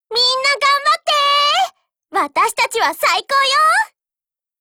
Cv-40135_warcry.wav